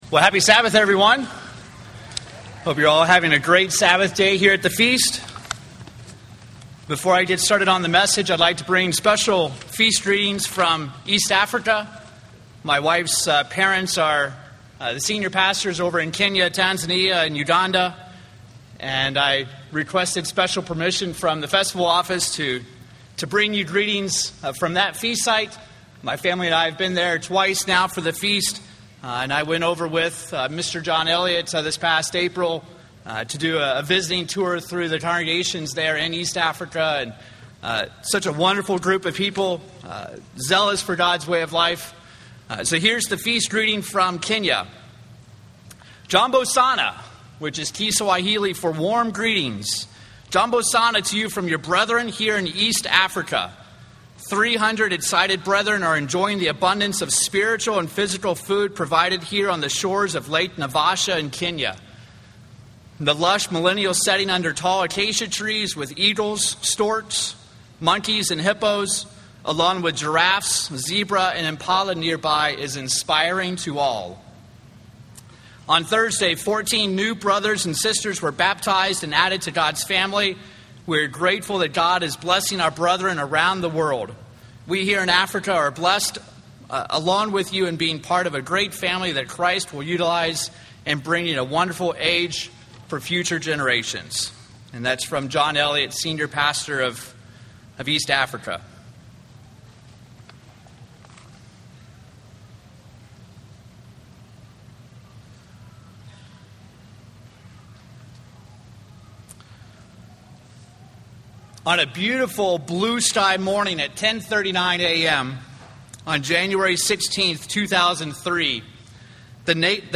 This sermon was given at the Wisconsin Dells, Wisconsin 2008 Feast site.